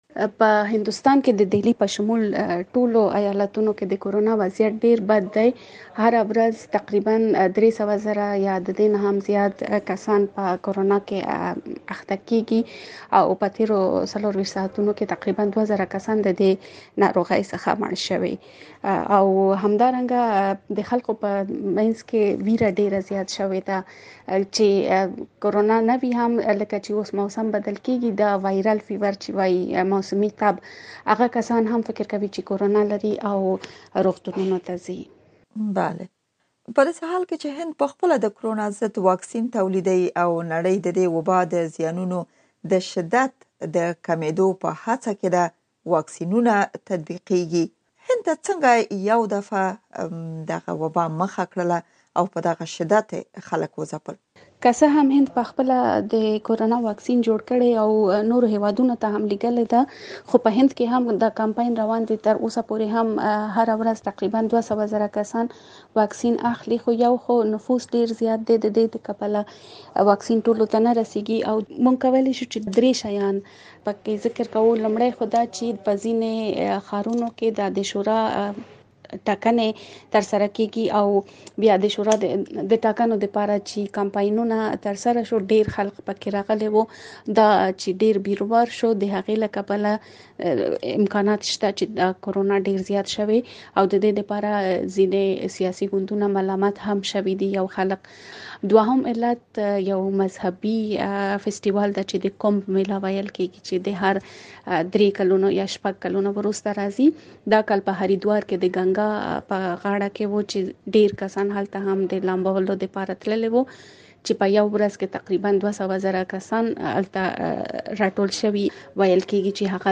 مرکې